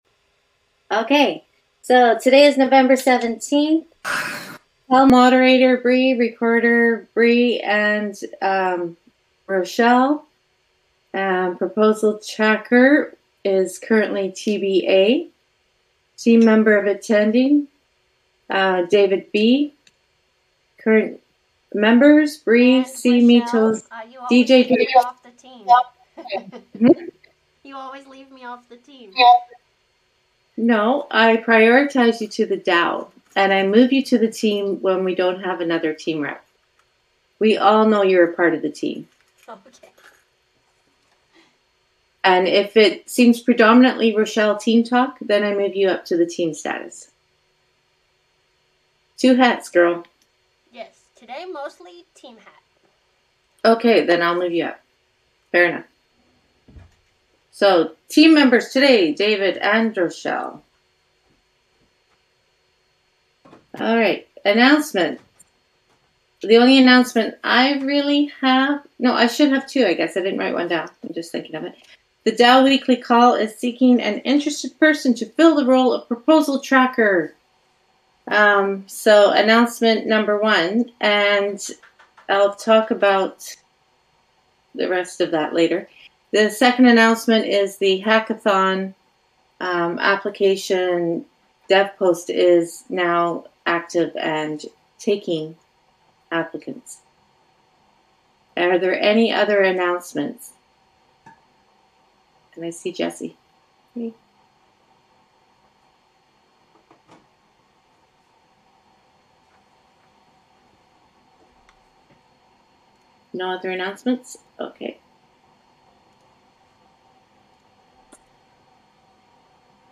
Please participate in the discussions – on the call or in the thread.